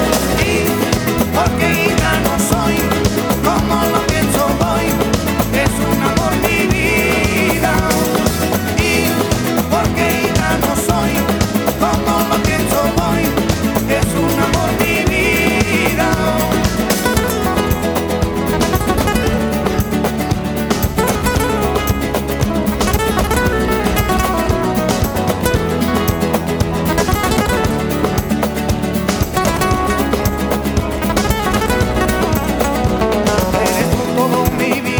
припев, мелодию нарезки
Latin